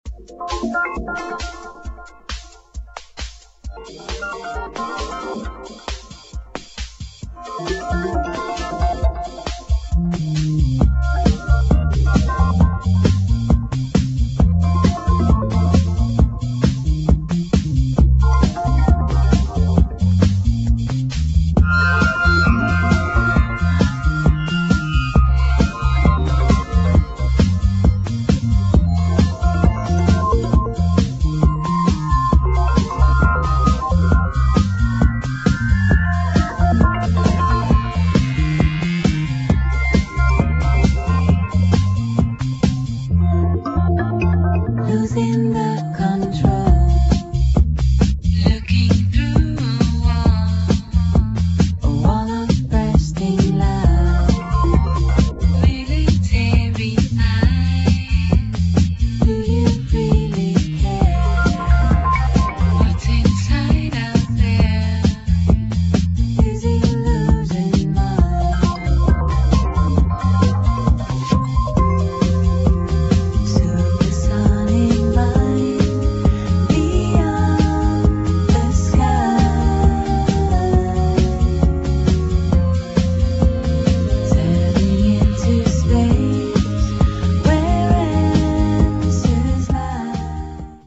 [ DISCO / HOUSE / ELECTRO / LEFTFIELD / ACID JAZZ ]